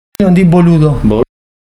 boludez_prononciation01.mp3